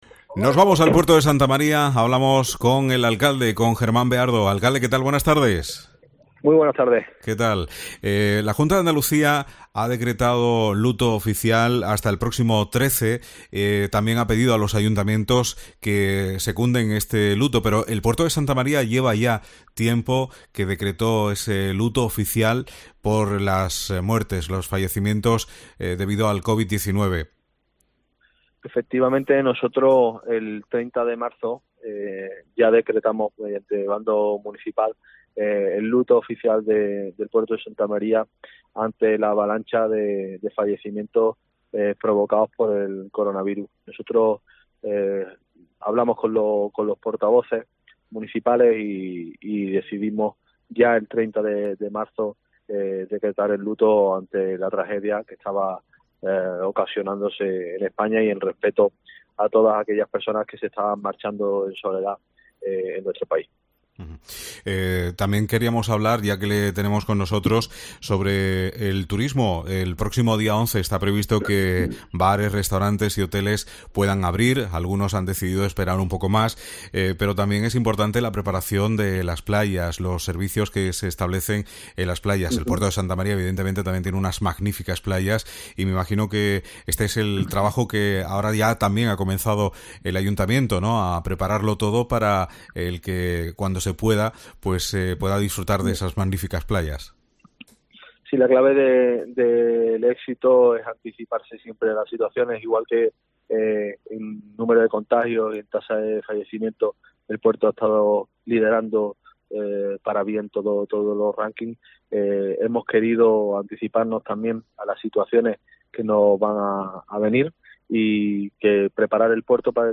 Germán Beardo, alcalde de El Puerto de Santa María